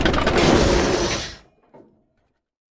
tank-brakes-1.ogg